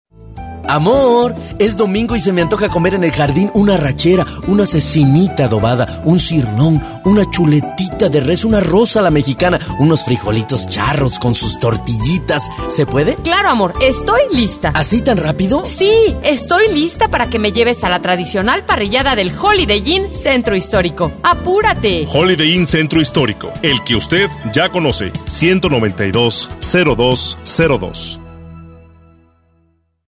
DEMOS
Spot Holiday Inn.mp3